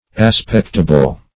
aspectable - definition of aspectable - synonyms, pronunciation, spelling from Free Dictionary
Search Result for " aspectable" : The Collaborative International Dictionary of English v.0.48: Aspectable \As*pect"a*ble\, a. [L. aspectabilis.]